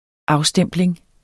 Udtale [ ˈɑwˌsdεmˀbleŋ ]